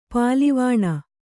♪ pālivāṇa